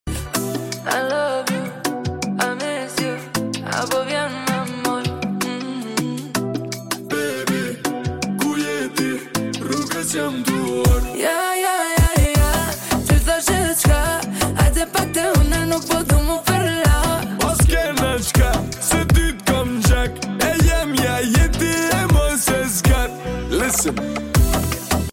slowed+Reverb